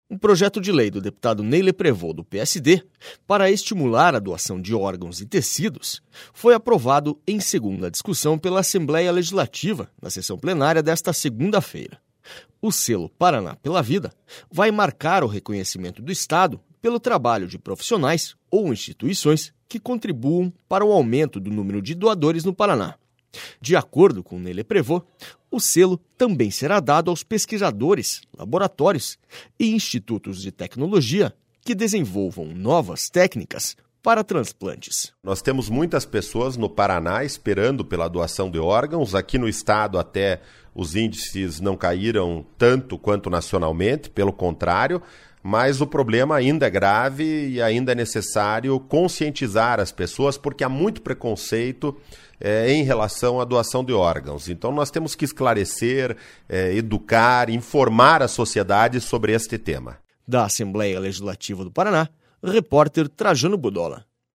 SONORA NEY LEPREVOST